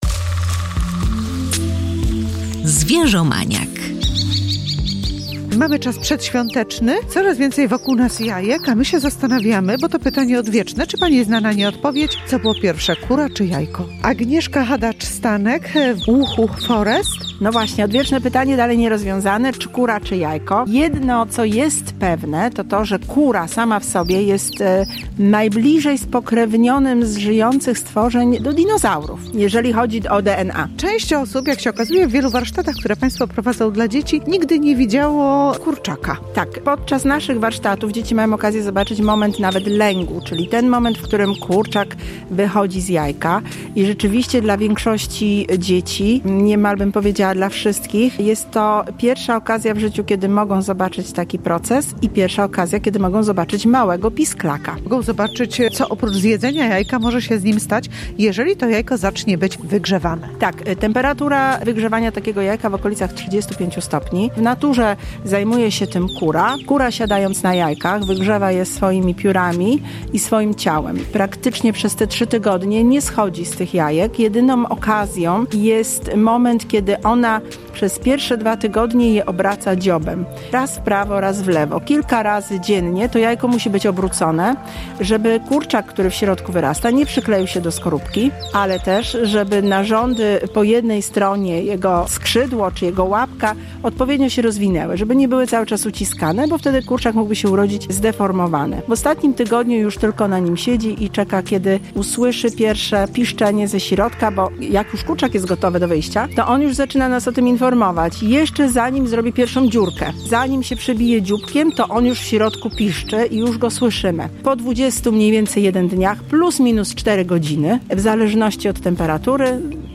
Fascynujący świat ptasich jaj i kwok – warsztaty edukacyjne dla dzieci i dorosłych